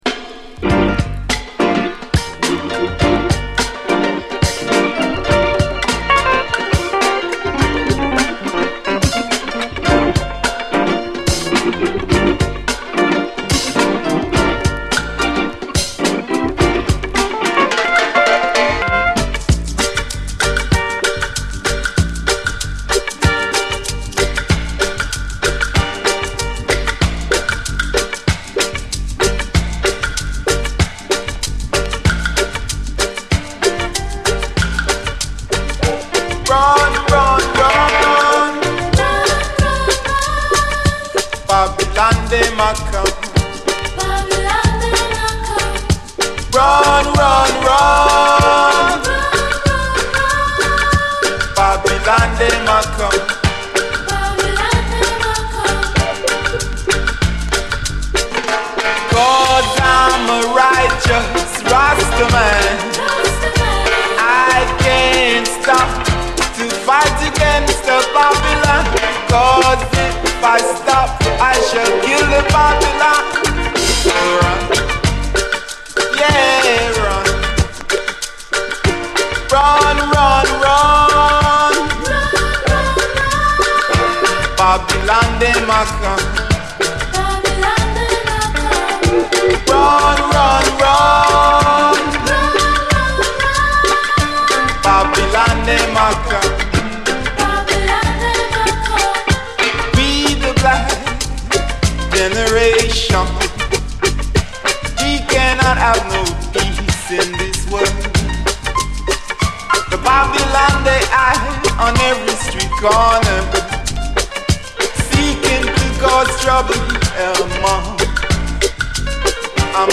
知られざる90年UK産ルーツ・レゲエのマイナー盤
ソウルそのものディスコそのものでありつつ、どこかラヴァーズ・フィーリングも漂うお洒落な最高ダンサー！
メロディカの音色が泣かせます。